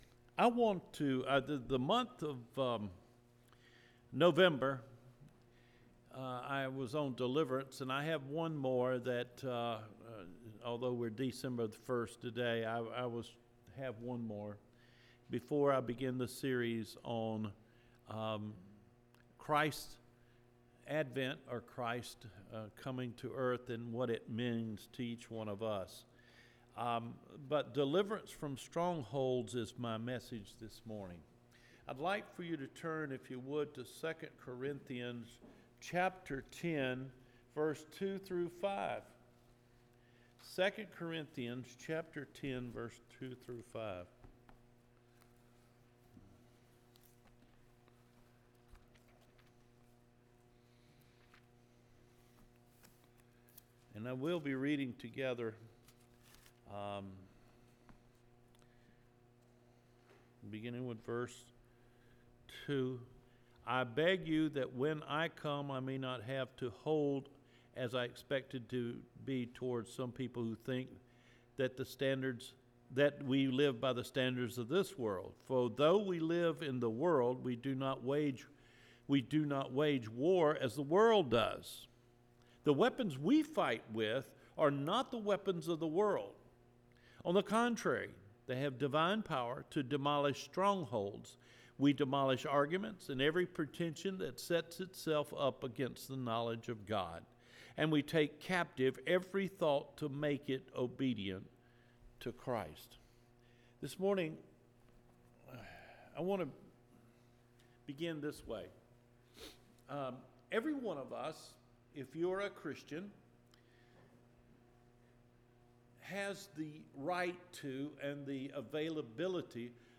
DECEMBER 1 SERMON – DELIVERANCE FROM STRONGHOLDS